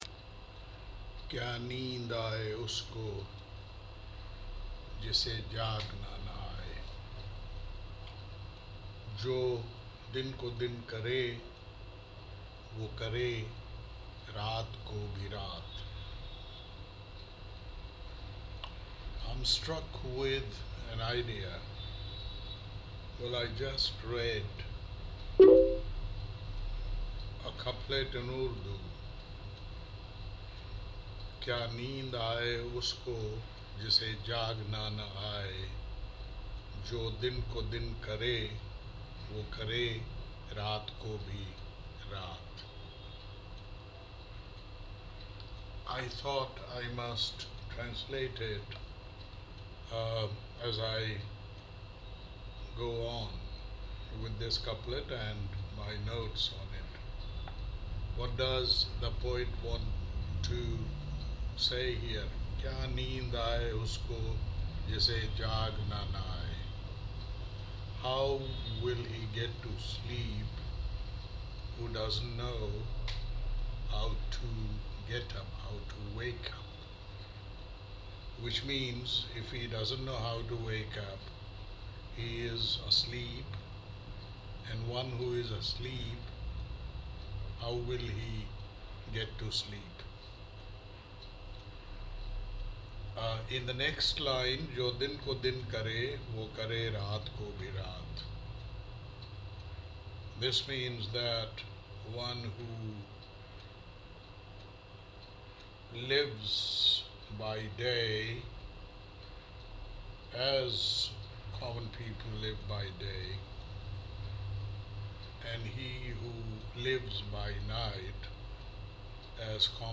This is a lighter vein exercise in impromptu translation of an Urdu couplet and its commentary in English.